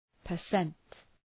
Προφορά
per-cent.mp3